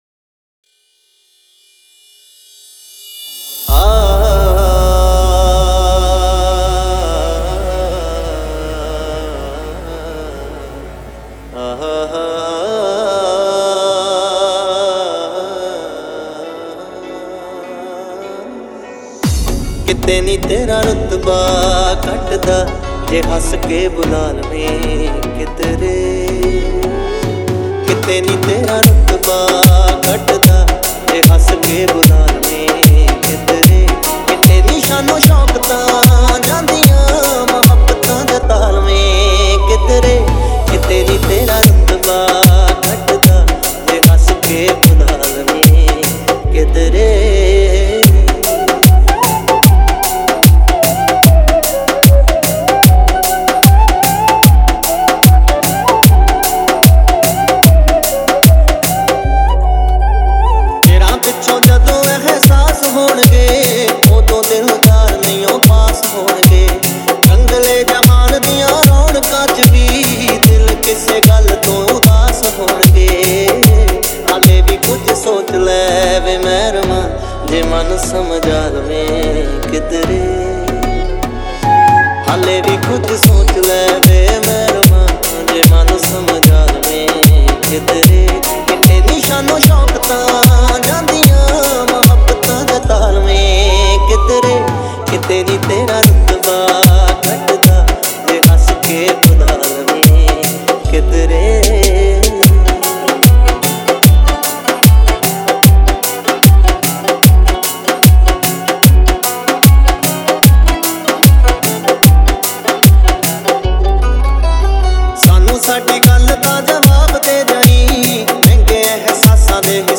Punjabi Remix